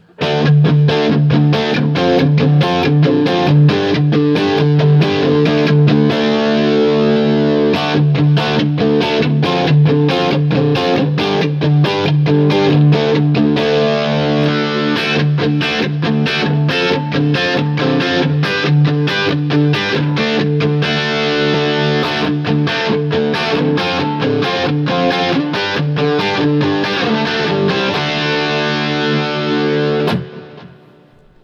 I put the guitar through my usual rig which is the Axe-FX Ultra into the QSC K12 speaker using the Tiny Tweed, JCM 800, and Backline settings.
BL D-Shaped Chords
Each recording goes though all of the pickup selections in the order: neck, both (in phase), both (out of phase), bridge.